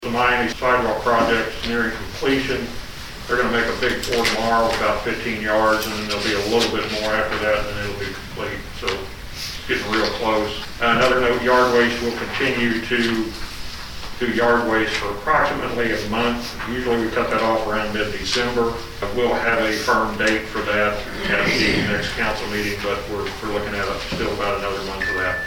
During the meeting of the Marshall City Council on Monday, November 16, Ward 3 Councilman Dan Brandt said a sidewalk project is nearing completion.